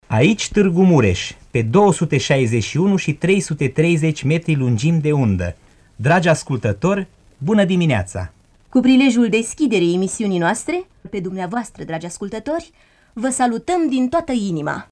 1-mar-semnal-deschidere.mp3